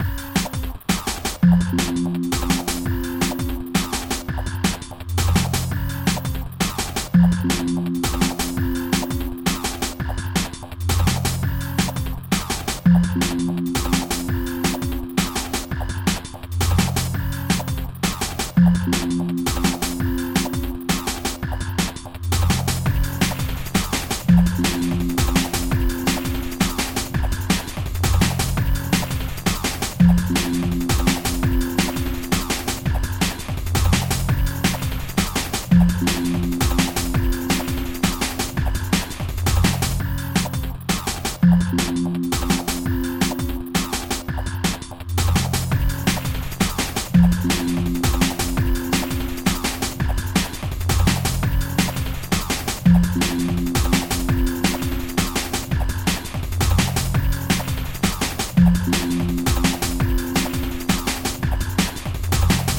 Pack from 1 to 5 [LOW quality].